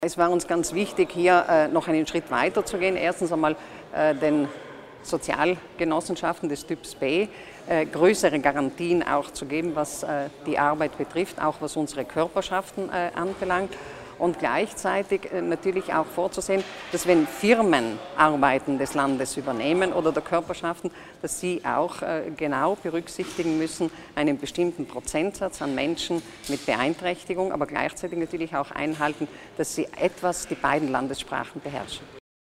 Landesrätin Stocker erläutert die Neuigkeiten in Sachen Sozialklausel